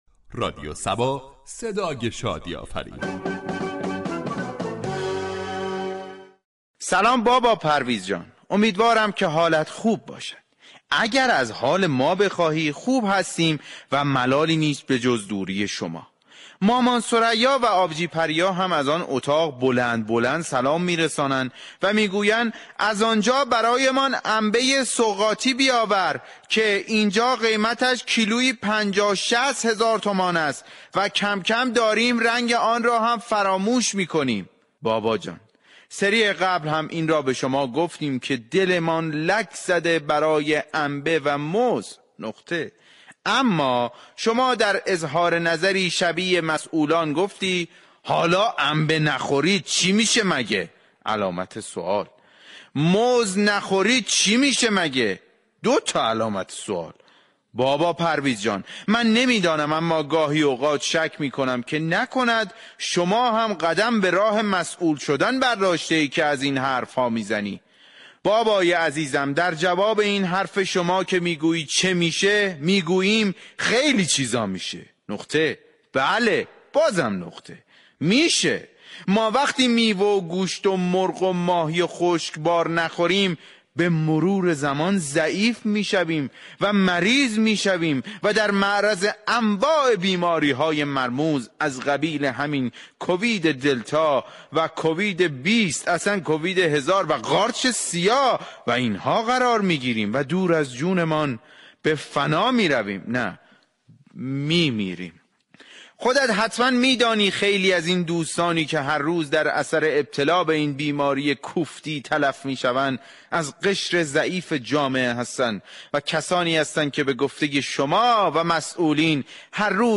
شهر فرنگ در بخش نمایشی با بیان طنز به موضوع "گرانی و كوچك شدن سفره های مردم "پرداخته است ،در ادامه شنونده این بخش باشید.